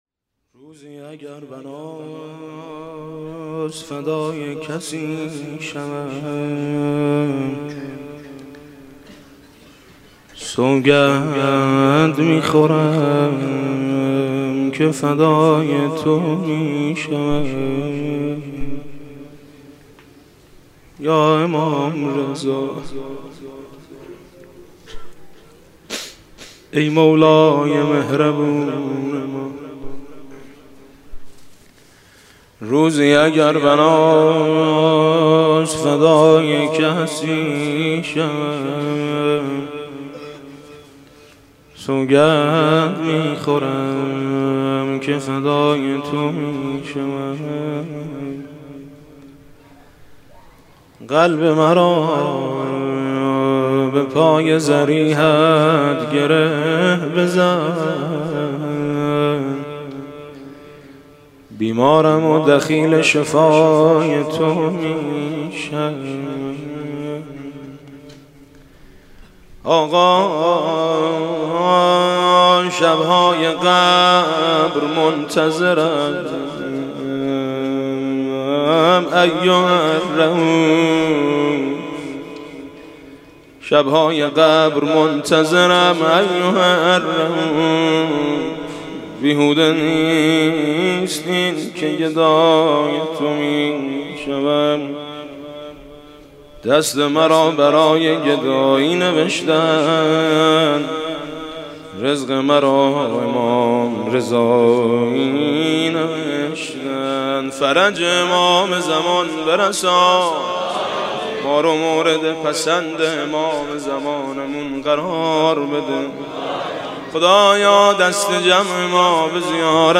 نجوا با امام رضا علیه السلام